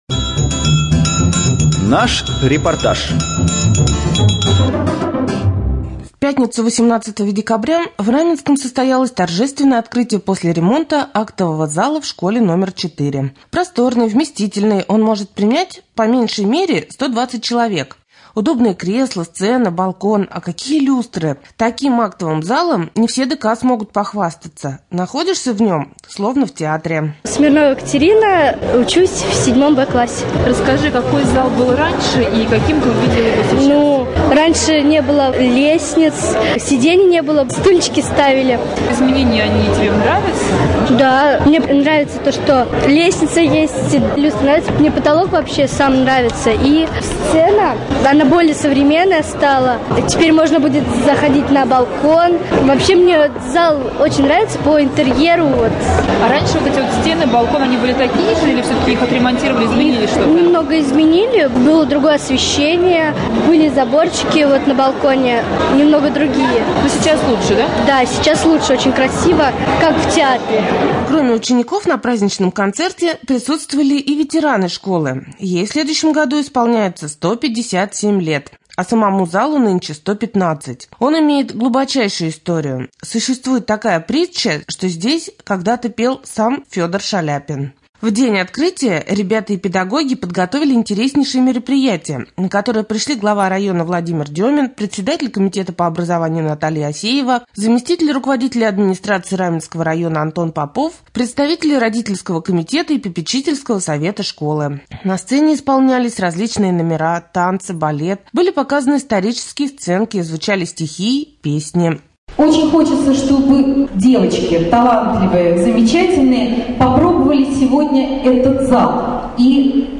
3.Рубрика «Специальный репортаж».